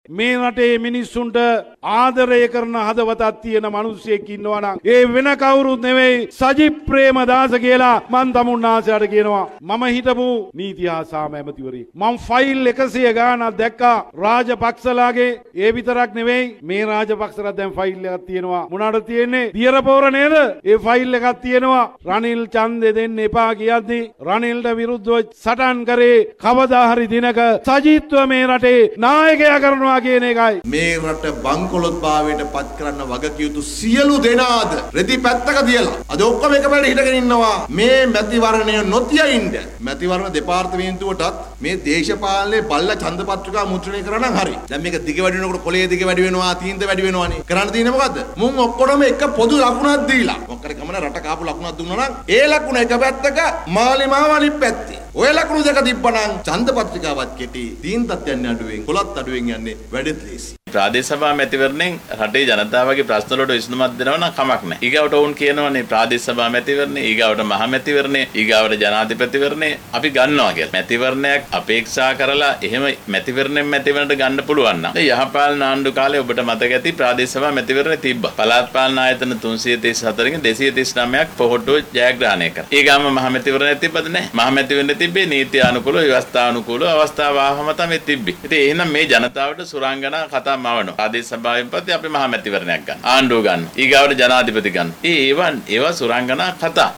මේ දේශපාලන වේදිකාවේ පක්ෂ විපක්ෂව දැක්වූ අදහස් කිහිපයක්.